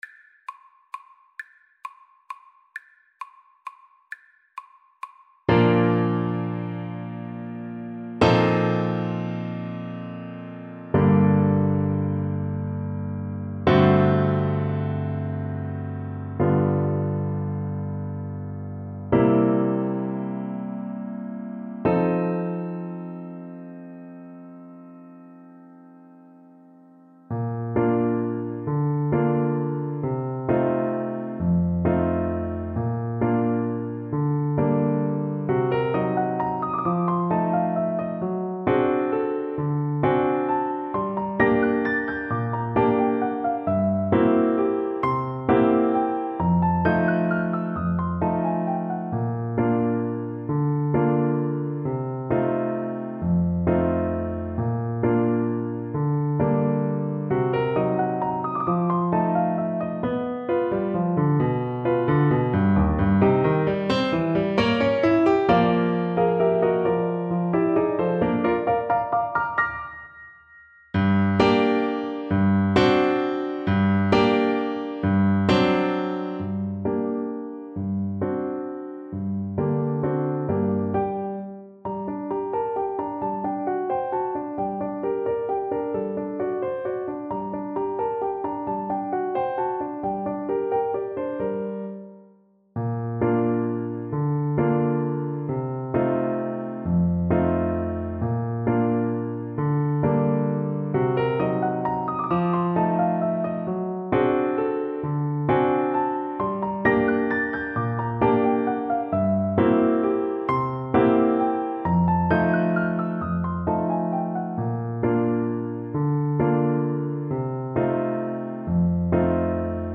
Largo e espressivo . = c. 44
3/4 (View more 3/4 Music)
Bb3-F5
Classical (View more Classical Trombone Music)